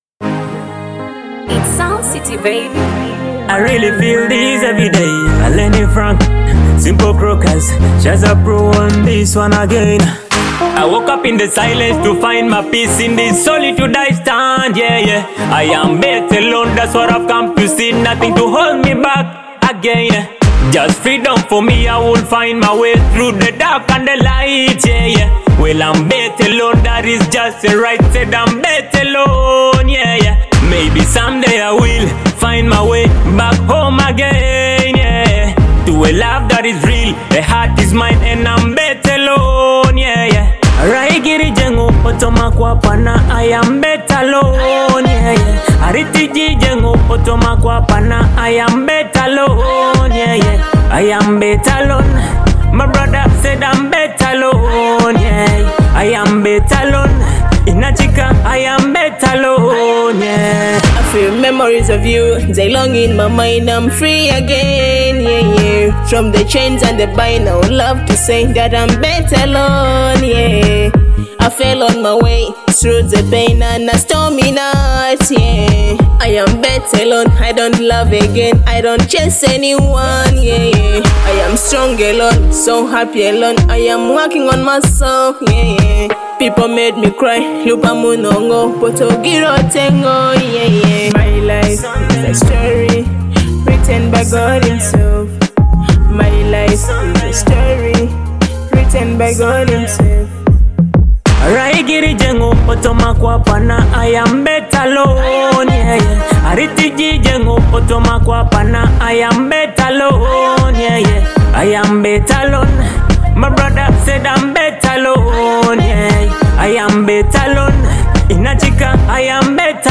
a soulful anthem of self-empowerment and emotional strength.